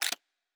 Weapon 02 Foley 2.wav